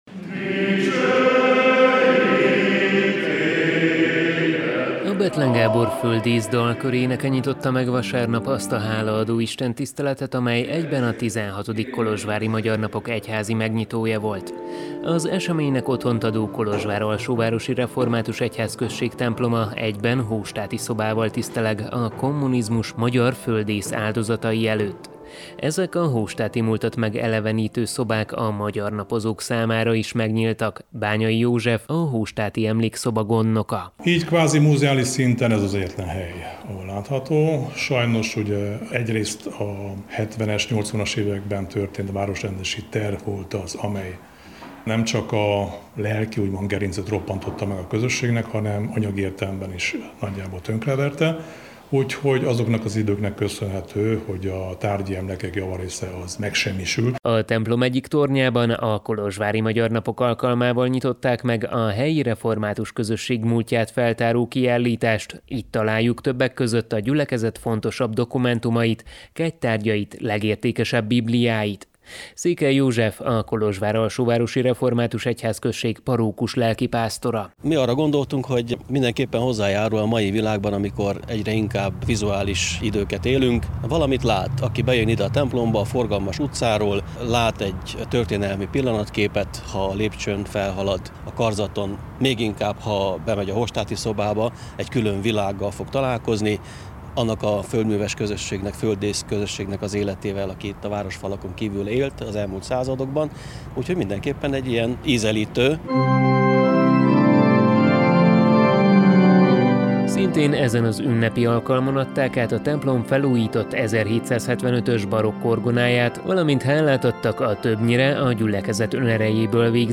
A Kolozsvár-Alsóvárosi Református Egyházközség templomában tartották az istentiszteletet.